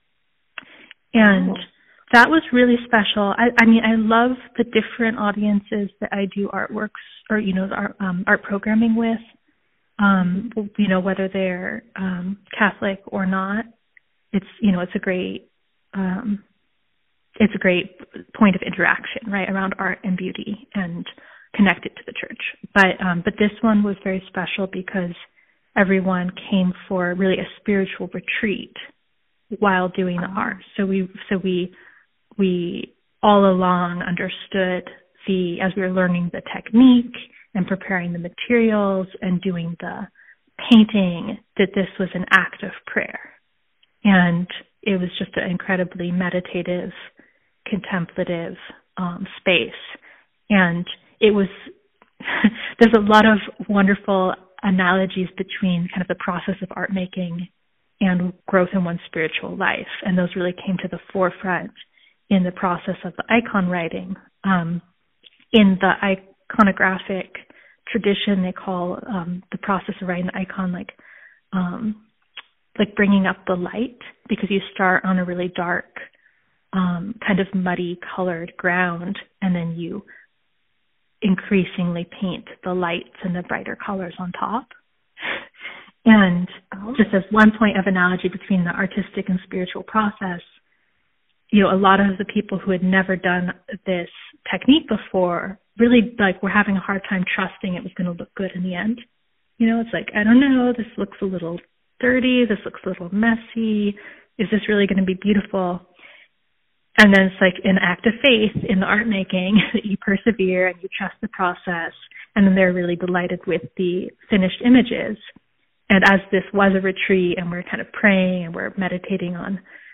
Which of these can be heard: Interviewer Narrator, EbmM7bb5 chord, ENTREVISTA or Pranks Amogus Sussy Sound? Interviewer Narrator